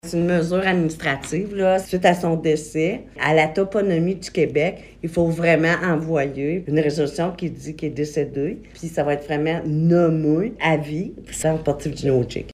La mairesse de Maniwaki, Francine Fortin, confirme l’information :